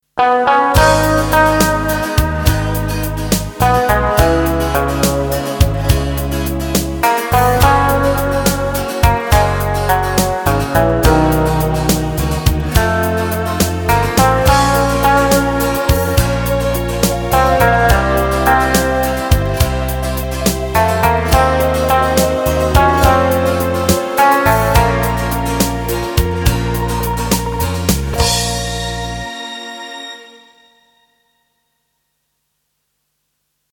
Гитара, похожая на Big Western Gtr (пример внутри)